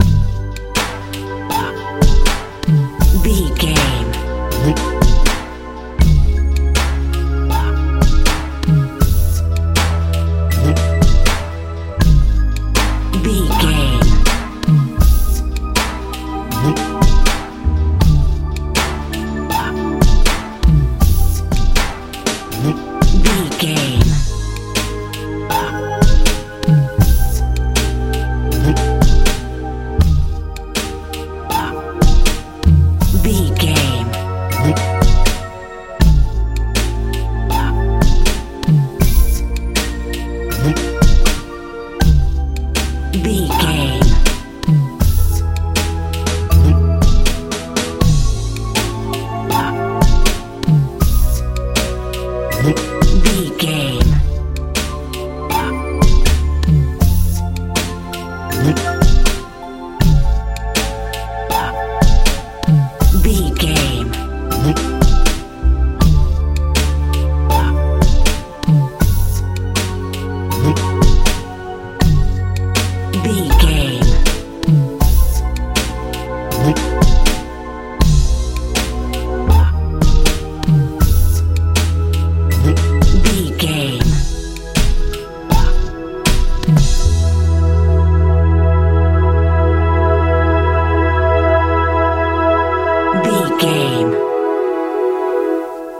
hip hop feel
Ionian/Major
soft
strange
synthesiser
bass guitar
piano
80s
90s